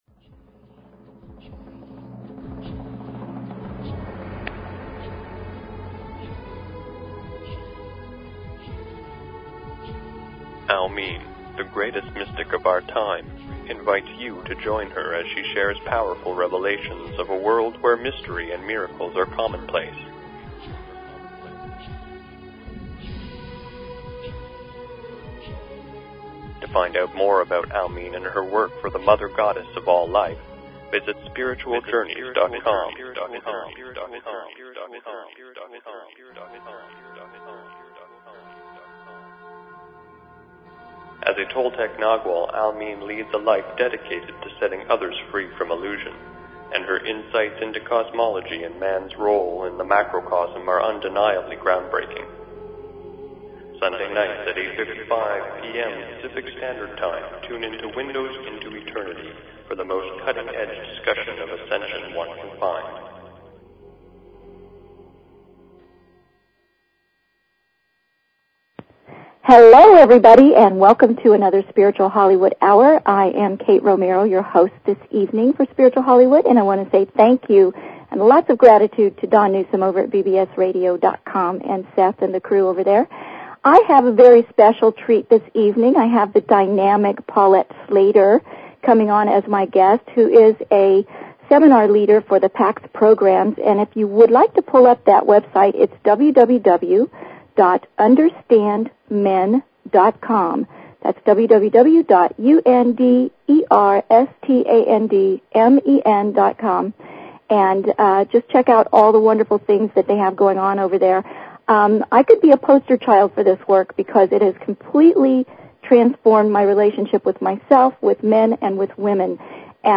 Talk Show Episode, Audio Podcast, Spiritual_Hollywood and Courtesy of BBS Radio on , show guests , about , categorized as